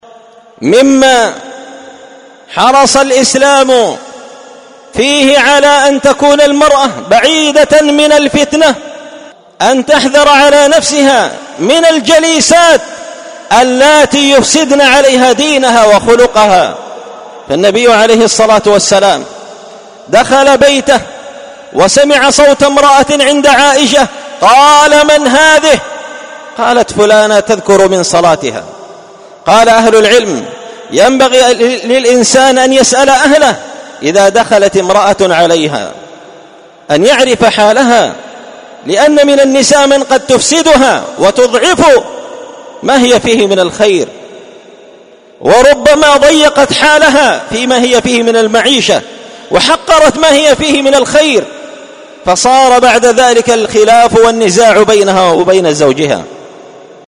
سلسلة مقتطفات من خطبة جمعة بعنوان حراسة الفضيلة وحماية المجتمع من الرذيلة⏸المقتطف السابع⏸السبب الخامس من الأسباب التي تحرس وتحمى بها الفضيلة حذر المرأة من الجليسات المفسدة
دار الحديث بمسجد الفرقان ـ قشن ـ المهرة ـ اليمن